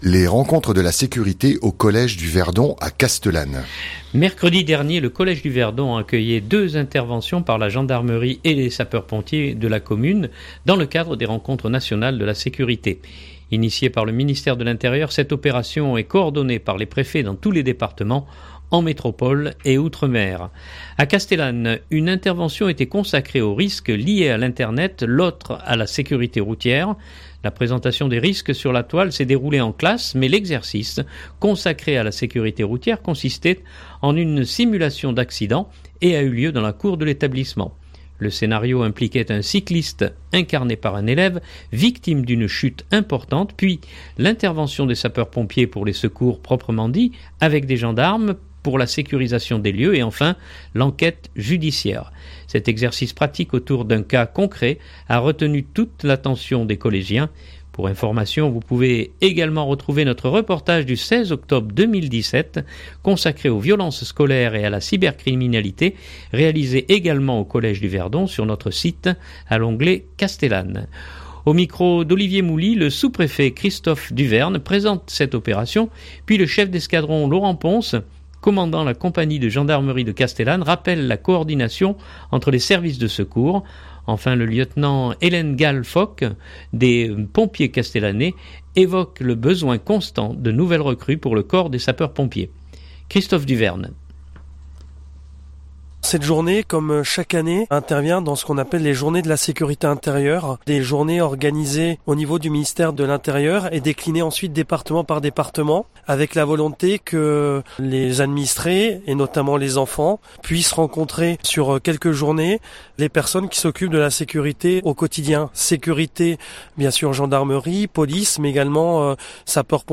Mercredi dernier, le collège du Verdon accueillait deux interventions par la gendarmerie et des sapeurs-pompiers de la commune, dans le cadre des rencontres nationales de la sécurité.